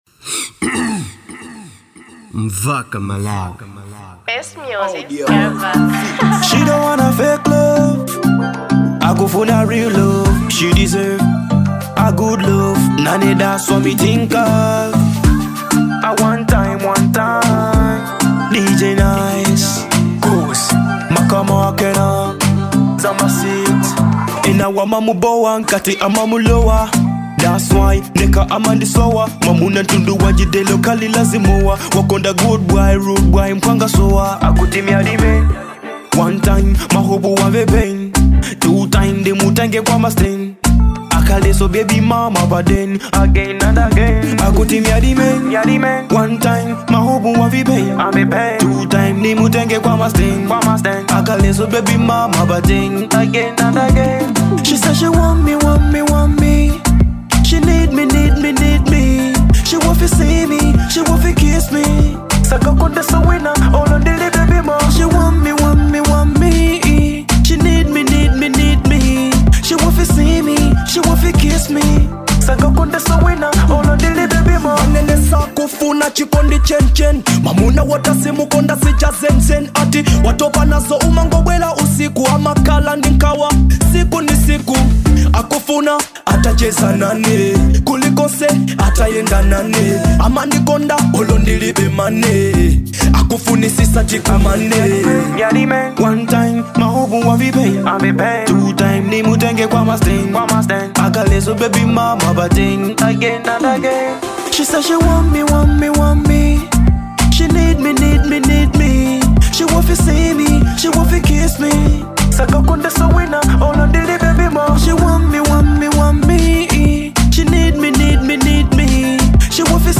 type: Dancehall